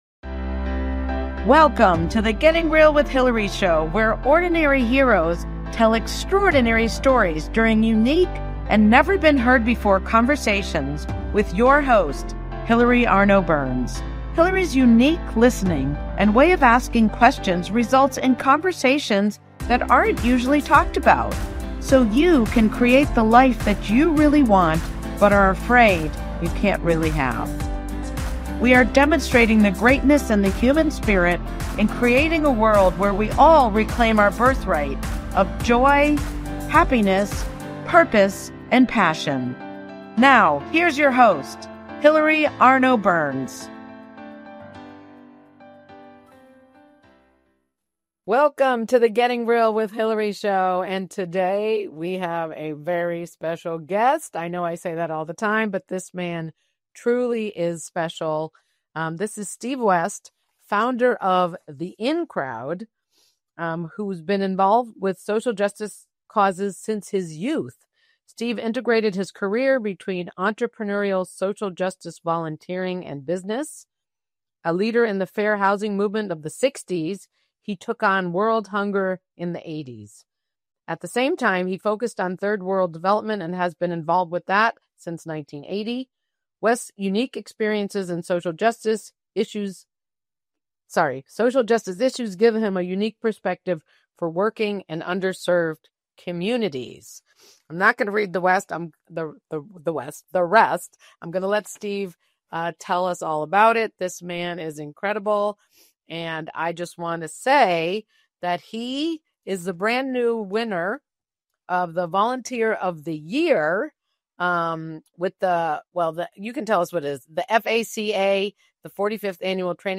Talk Show Episode
Join us for this inspiring and mind-awakening conversation.